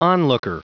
Prononciation du mot onlooker en anglais (fichier audio)
Prononciation du mot : onlooker